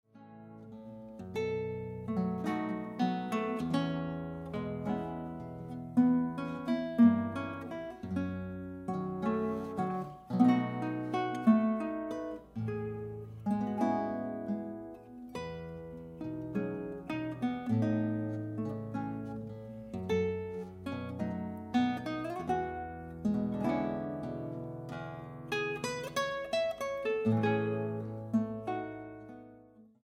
Grabado en la Sala Julián Carrillo de Radio UNAM